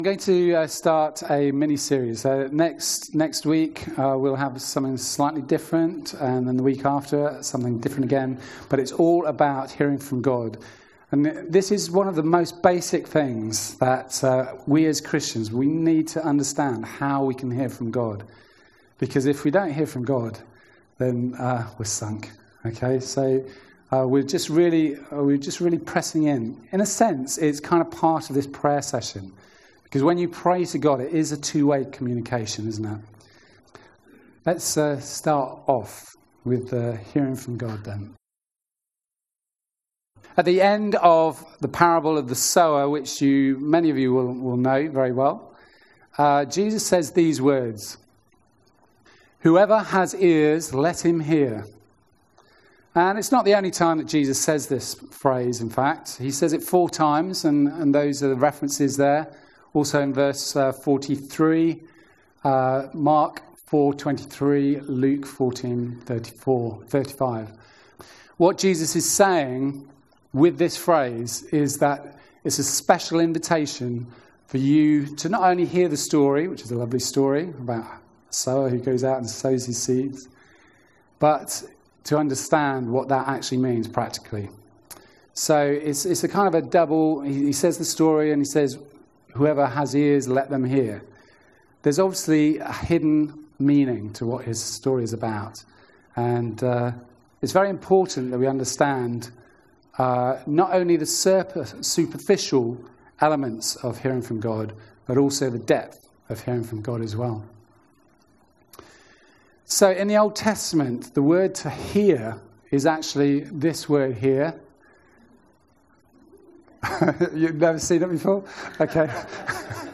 14 July 2019 sermon (32 minutes)